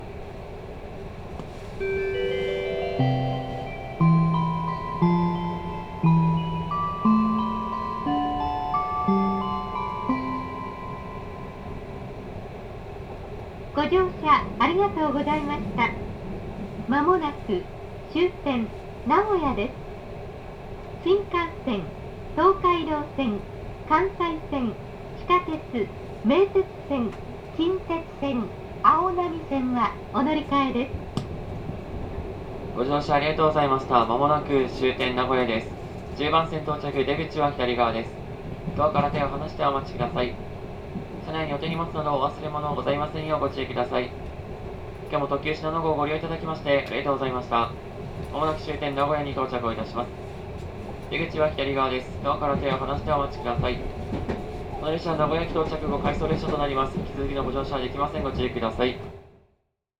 千種では地下鉄へ乗り換える乗客が降り、車内はさらに静けさを増す。
やがて、聞き慣れたJR東海特急用のチャイムが流れ始めた。
0013chikusa-nagoya.mp3